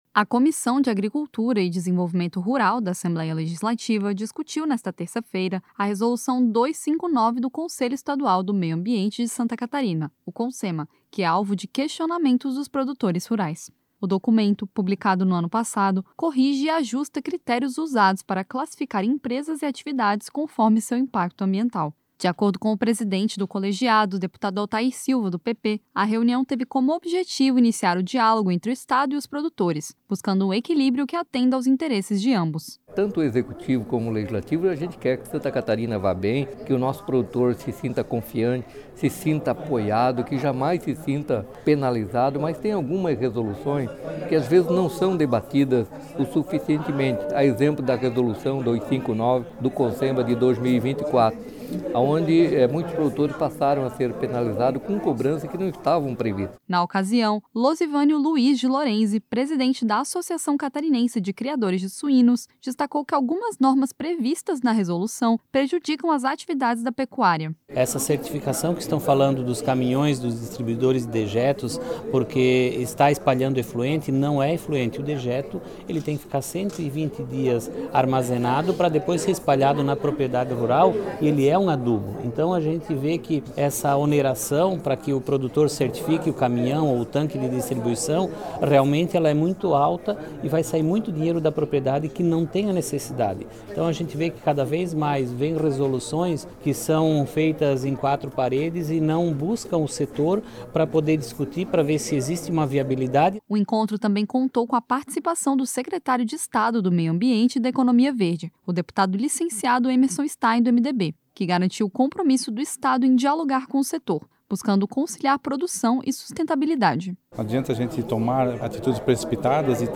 Entrevistas com:
- deputado Altair Silva (PP), presidente da Comissão de Agricultura;
- deputado licenciado Emerson Stein (MDB), secretário de Estado do Meio Ambiente e da Economia Verde;